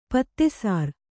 ♪ pattesār